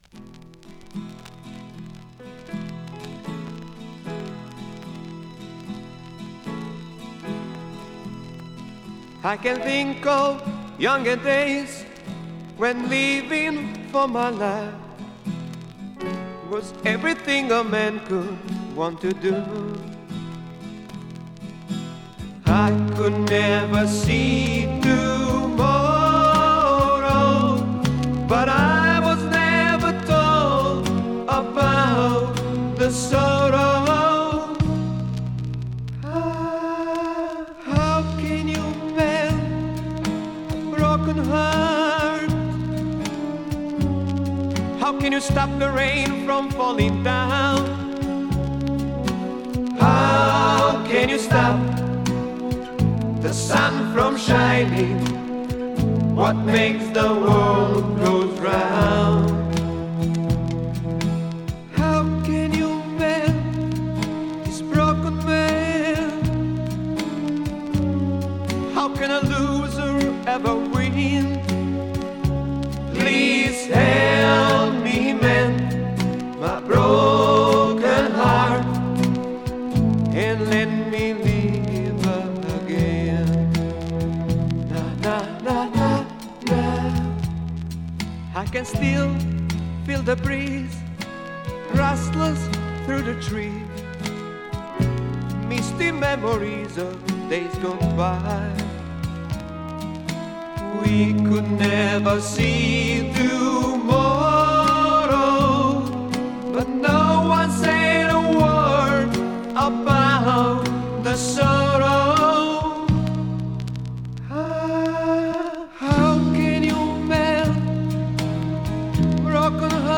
Vocals, Piano, Fender Rhodes 88,
Bass
Vocals, Drums, Percussion
Vocals, Guitars, Mandolin